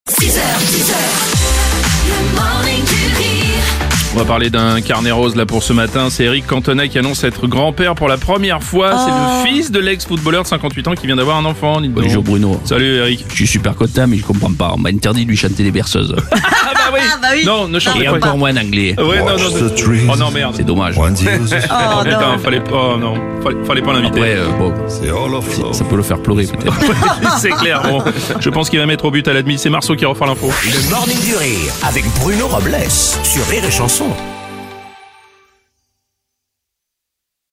débriefe l’actu en direct à 7h30, 8h30, et 9h30.